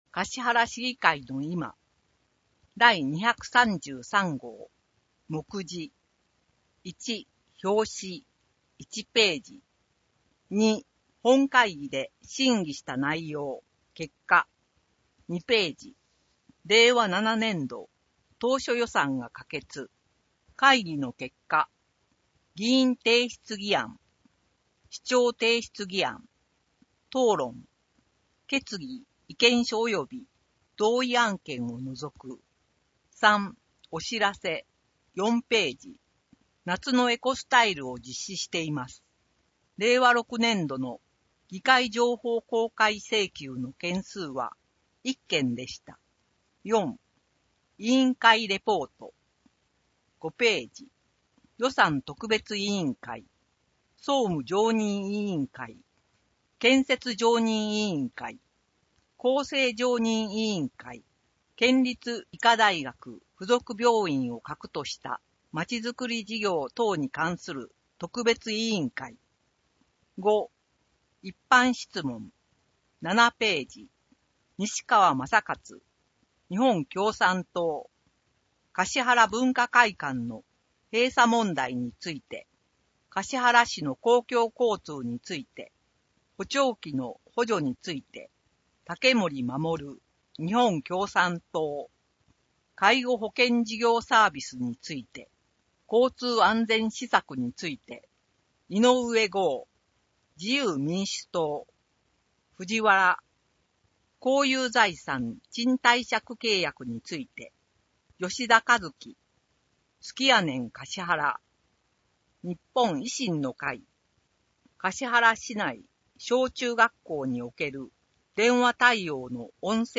音訳データ
かしはら市議会のいま233号 (PDFファイル: 9.1MB) 音訳データ かしはら市議会のいま第233号の音訳をお聞きいただけます。 音訳データは、音訳グループ「声のしおり」の皆さんが音訳されたものを使用しています。